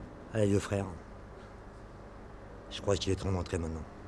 (on l'entend encore un peu mais ça doit suffire pour l'usage...)
sans_electro_spectr.wav